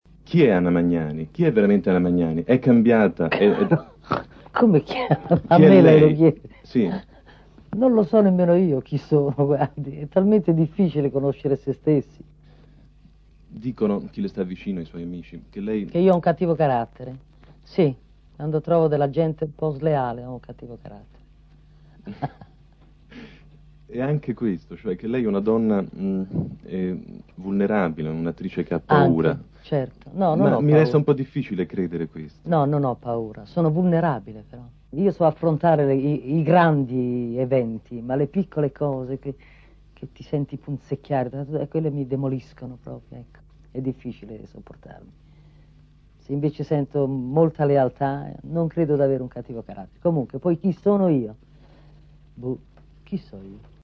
Lello Bersani intervista Anna Magnani, 1971.